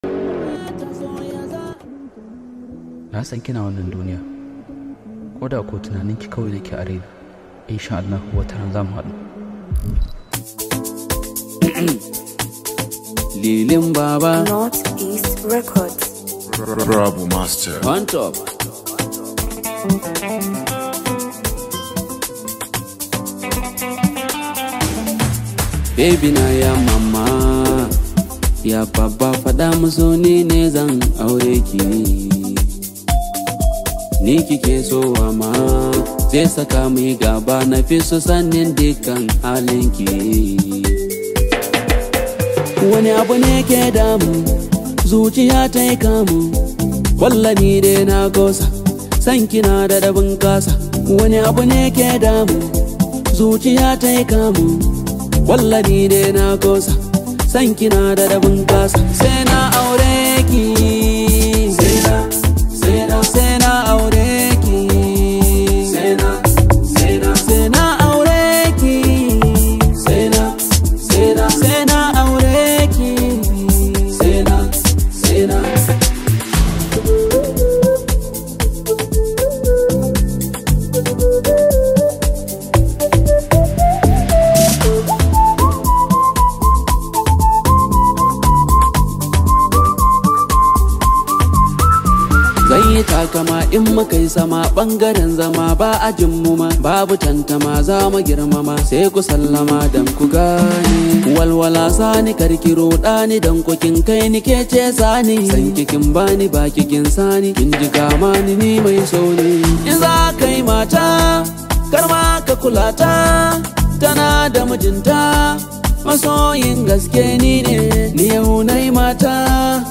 highly celebrated Hausa Singer